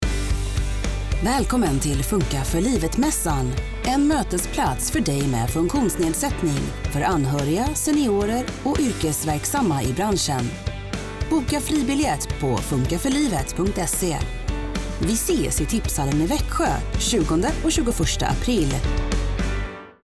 Radioreklam